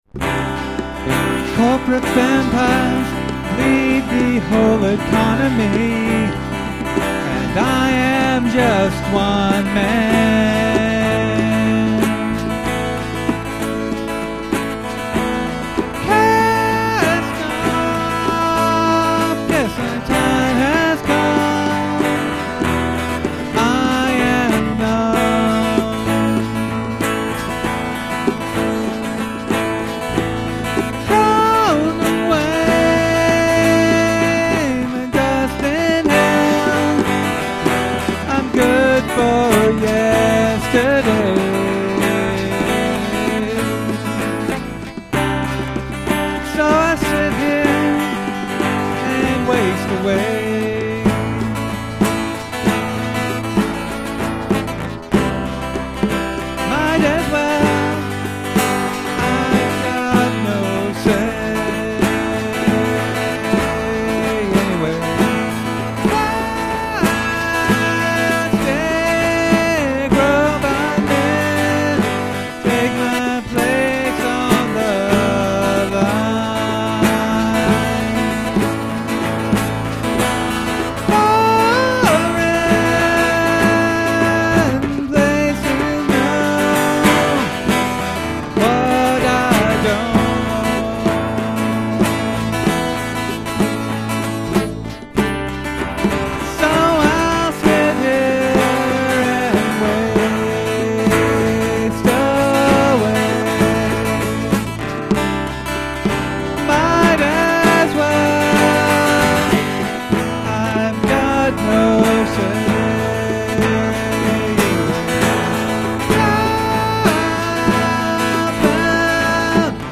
Unless otherwise noted, they're all originals, and I'm playing everything and singing.
The drums I've programmed as quickly as possible, but it's mostly a groove quantize over a couple of bass patterns.